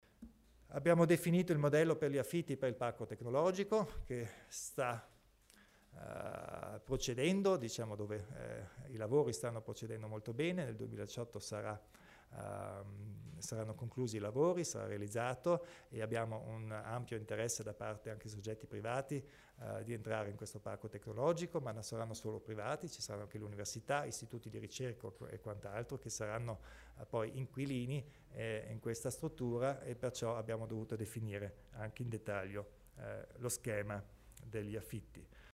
Il Presidente Kompatscher illustra i criteri per i canoni d'affitto degli spazi NOI